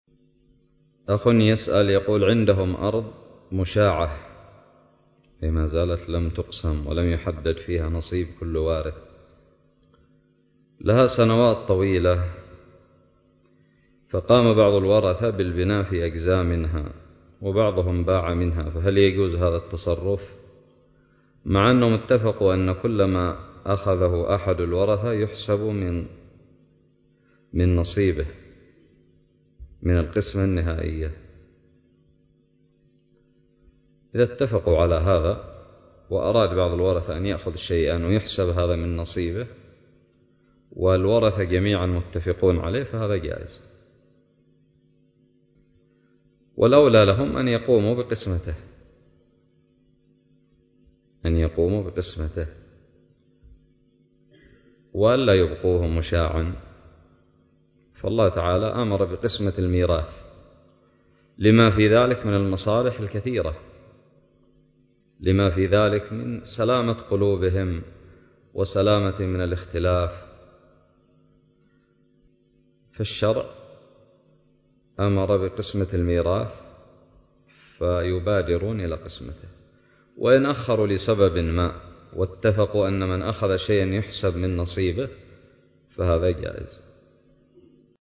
:العنوان فتاوى عامة :التصنيف 1444-8-3 :تاريخ النشر 45 :عدد الزيارات البحث المؤلفات المقالات الفوائد الصوتيات الفتاوى الدروس الرئيسية هناك أرض مشاعة قام بعض الورثة بالبناء في جزء منها واتفقوا أن هذا..؟ سؤال قدم لفضيلة الشيخ حفظه الله